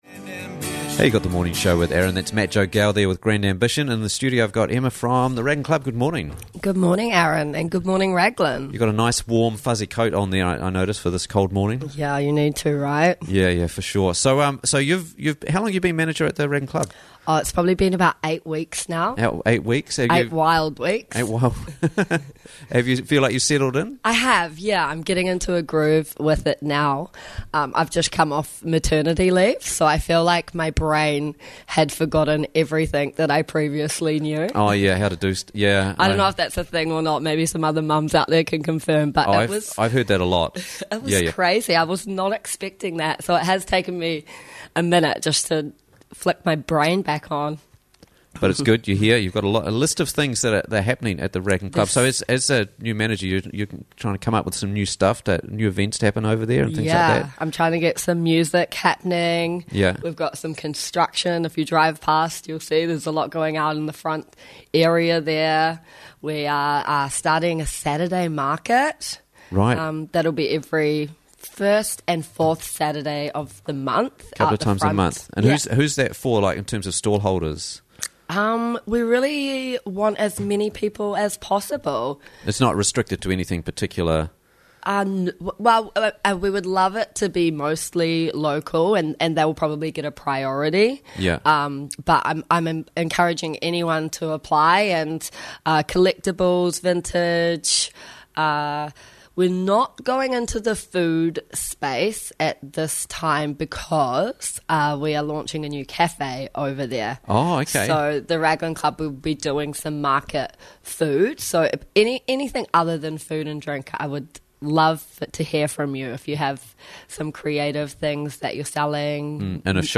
What's Going on at the Club - Interviews from the Raglan Morning Show